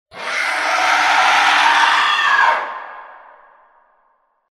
demon-sound_14225.mp3